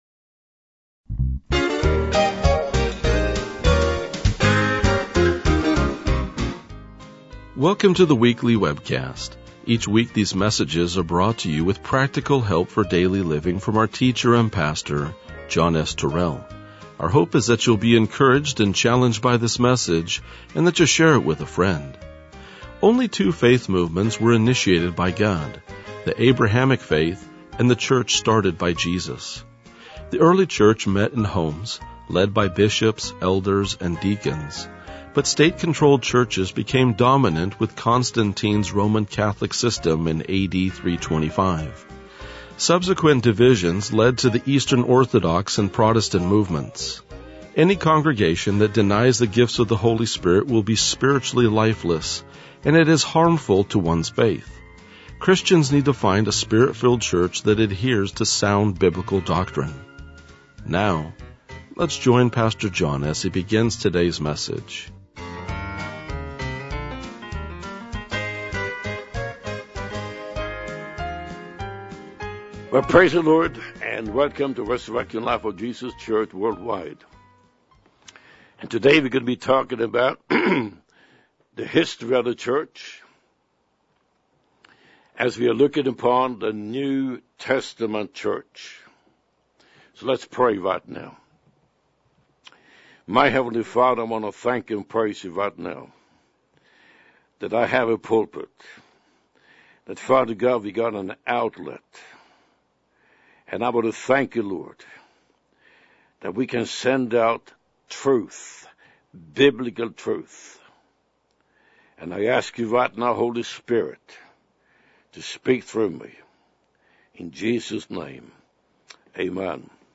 RLJ-2032-Sermon.mp3